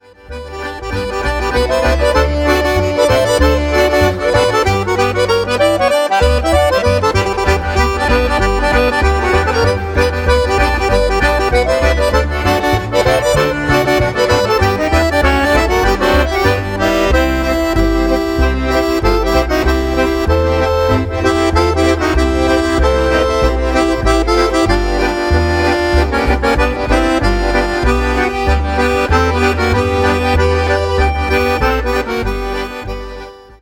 Fox